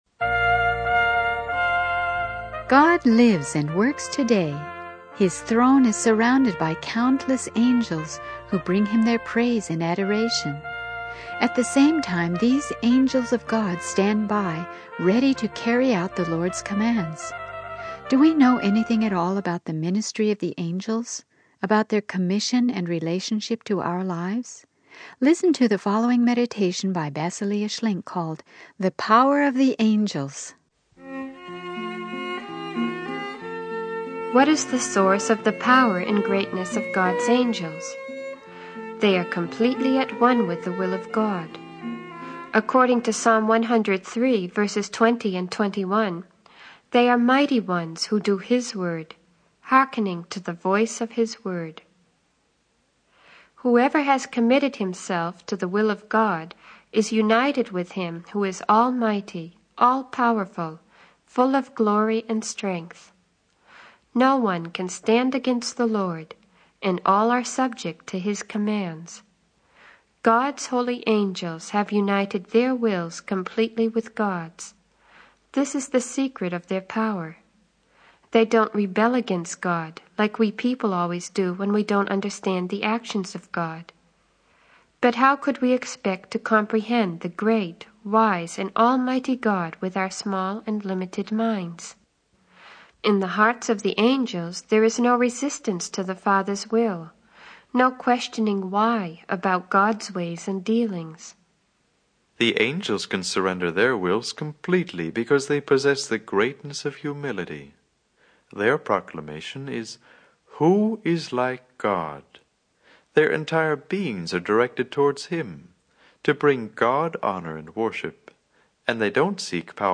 The video is a meditation on the power and ministry of God's angels. It emphasizes that the angels are completely aligned with the will of God and obedient to His commands.